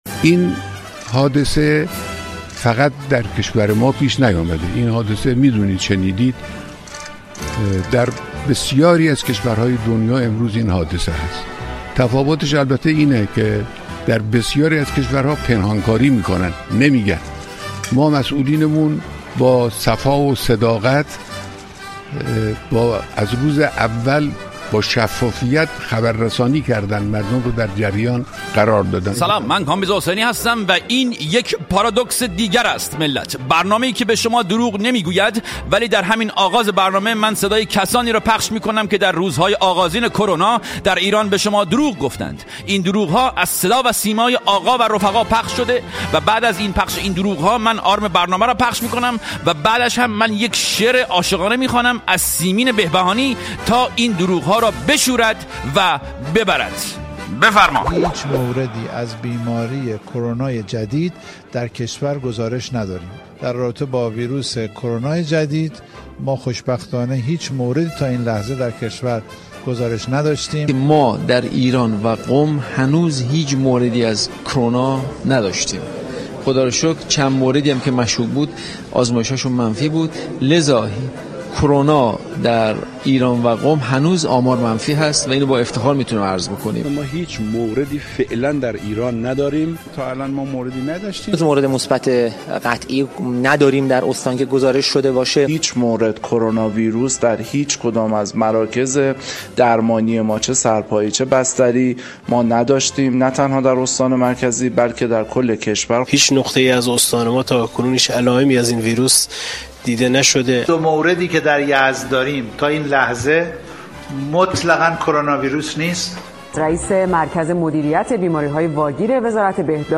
سلام من کامبیز حسینی هستم و این یک پارادوکس دیگر است ملت!
در ابتدای برنامه این هفته من صدای کسانی را پخش می‌کنم که در روزهای آغازین شیوع «ویروس منحوس کرونا» در ایران با نحسی بیشتری به شما و ما دروغ گفتند. این دروغ‌ها از صدا و سیمای آقا و رفقا پخش شده است.